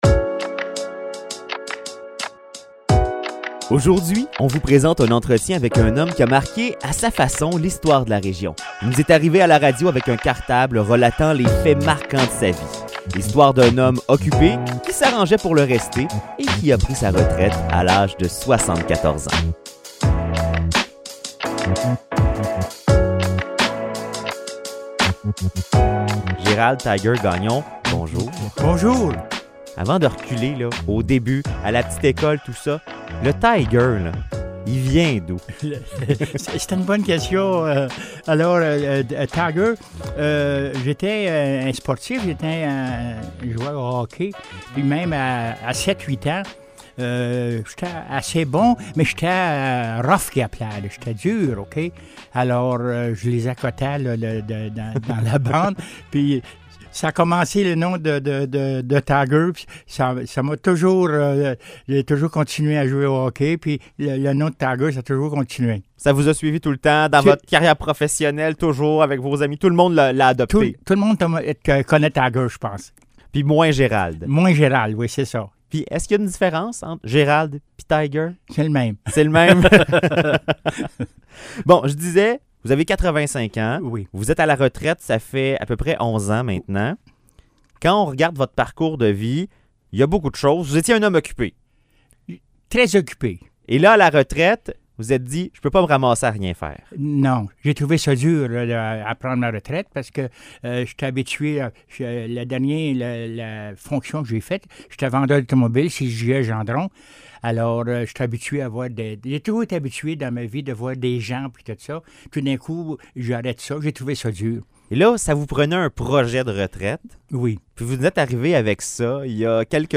On vous présente un entretien avec un homme qui a marqué, à sa façon, l’histoire de la région. Il nous est arrivé à la radio avec un cartable relatant les faits marquants de sa vie.